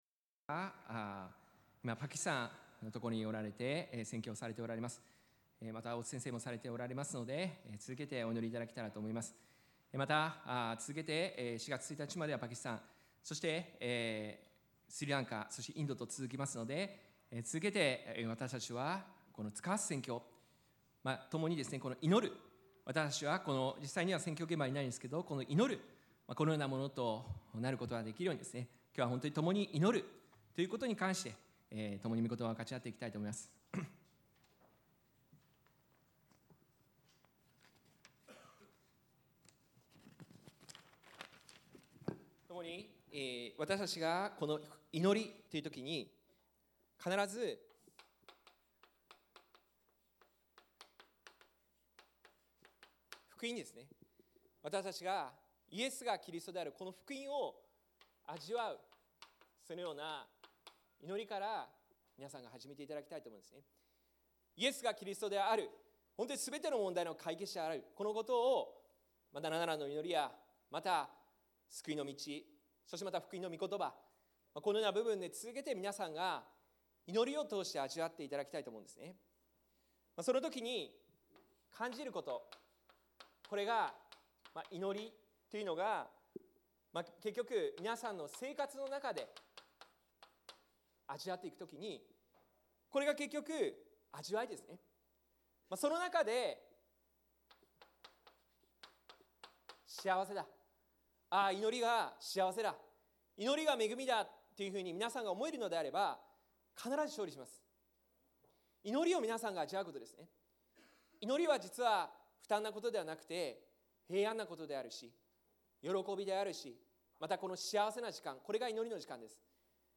新着 講壇のメッセージ